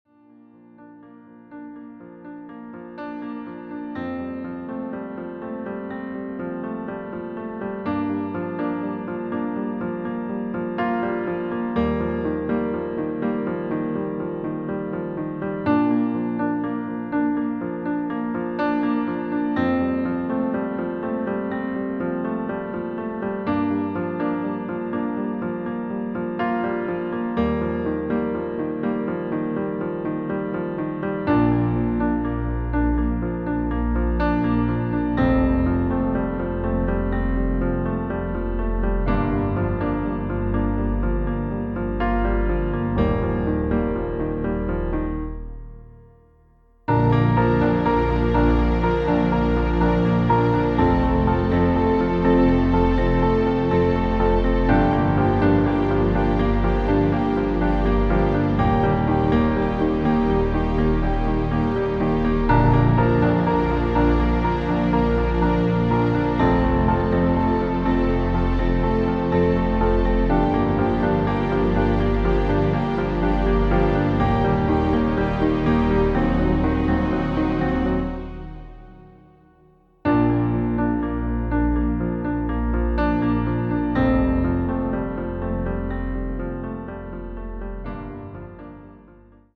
• Tonart: Bb Dur, C Dur, D Dur, E Dur
• Das Instrumental beinhaltet NICHT die Leadstimme
Klavier / Streicher